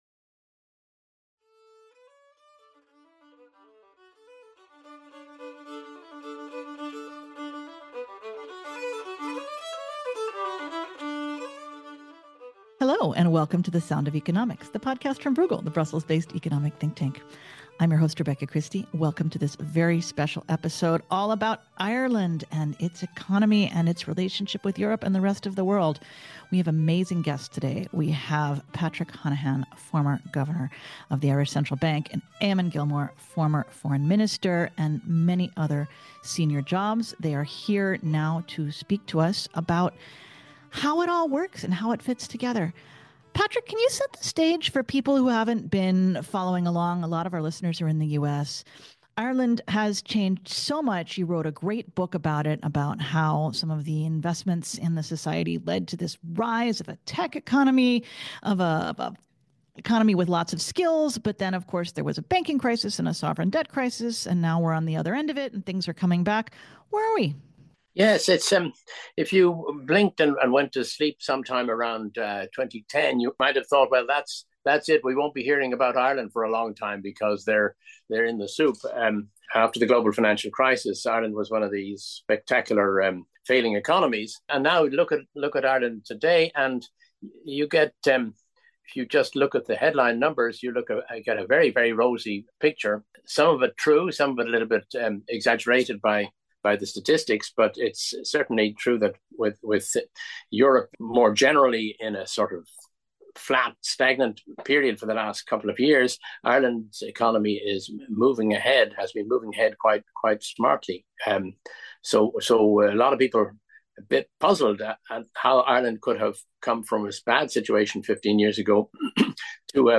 A conversation on Ireland’s economic recovery, housing crisis, and international role